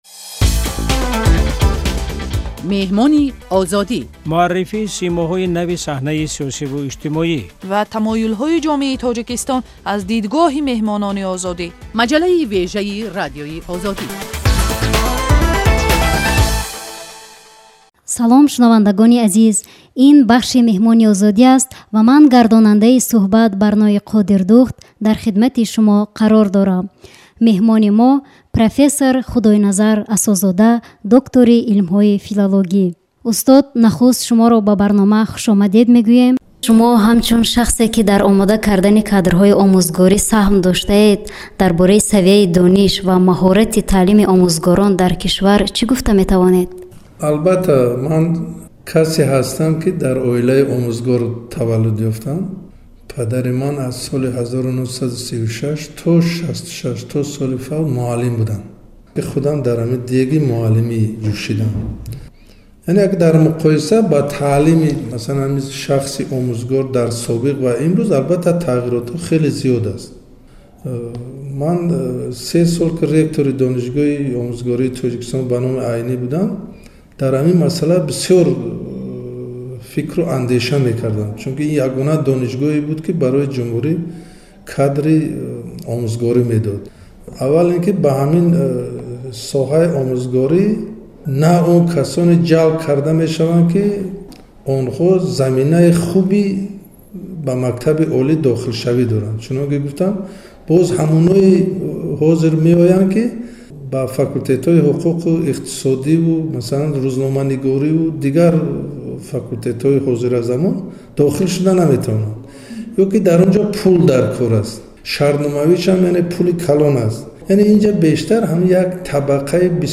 Адабиётшиноси маъруф дар барномаи "Меҳмони Озодӣ" бо як сӯҳбати хеле ошкор дар бораи худи ӯ ва ончи ки дар илму фарҳанги Тоҷикистон мегузарад.